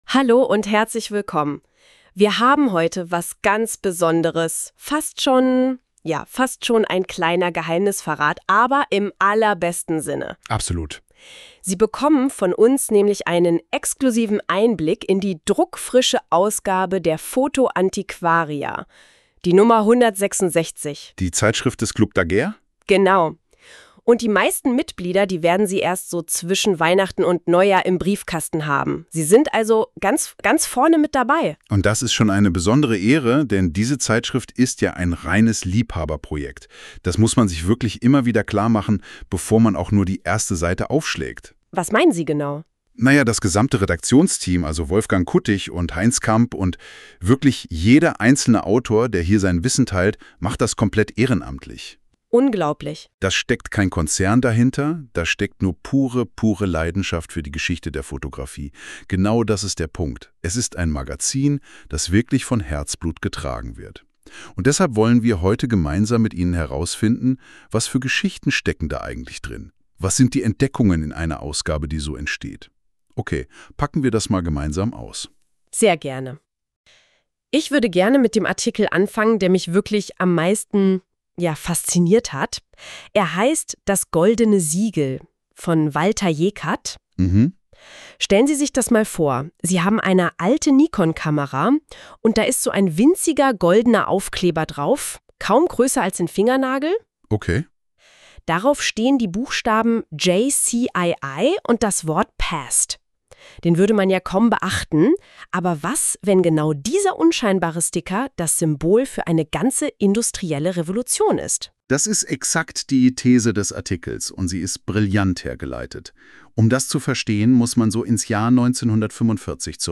KI-generierte Podcasts
Nach dem großen Erfolg der KI-generierten Podcasts zur Ausgabe 165 wurden auf vielfachen Wunsch erneut zwei Audio-Beiträge aus Originalartikeln produziert: